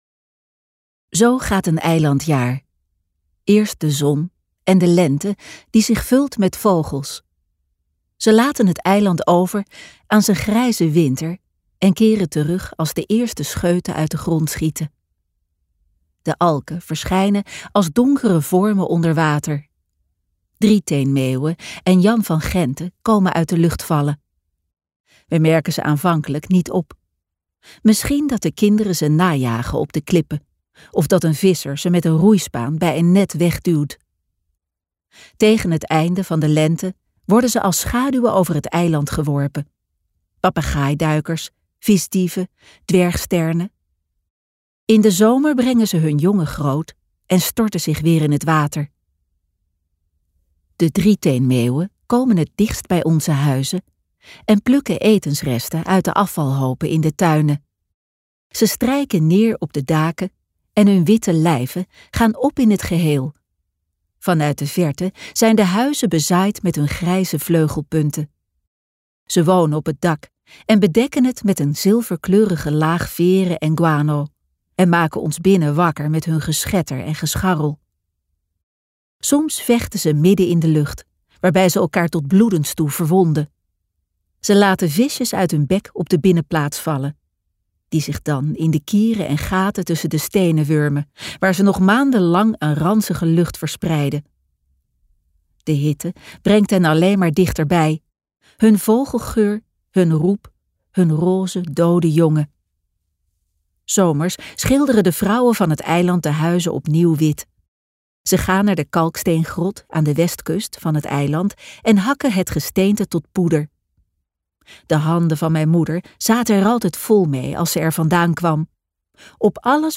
Walvistij luisterboek | Ambo|Anthos Uitgevers